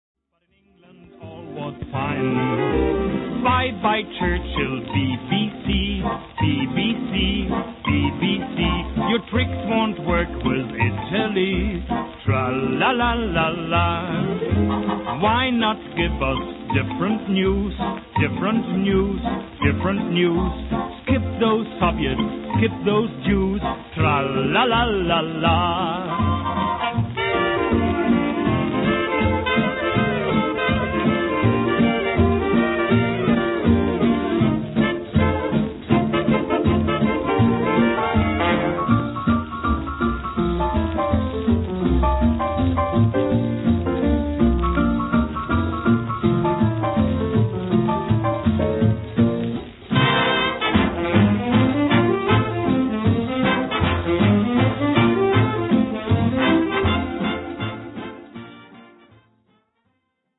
джазовый ансамбль